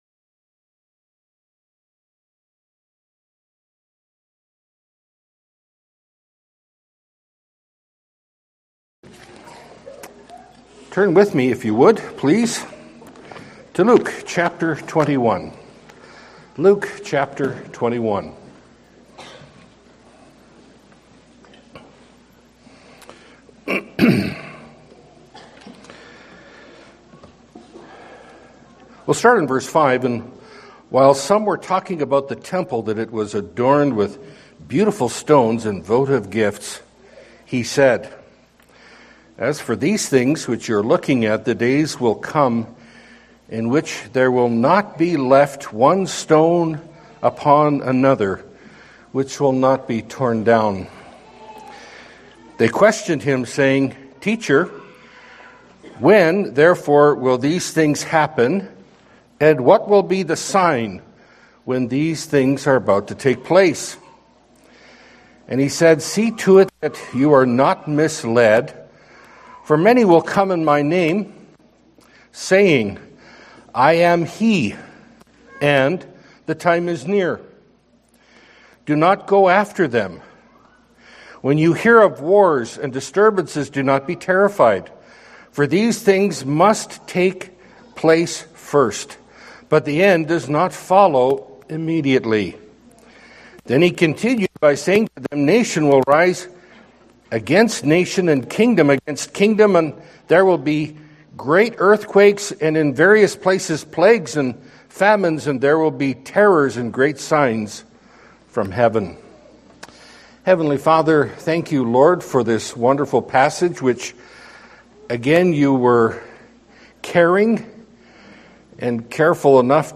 Pulpit Sermons